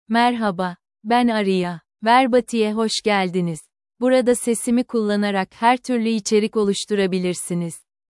Aria — Female Turkish (Turkey) AI Voice | TTS, Voice Cloning & Video | Verbatik AI
Aria is a female AI voice for Turkish (Turkey).
Voice sample
Female
Aria delivers clear pronunciation with authentic Turkey Turkish intonation, making your content sound professionally produced.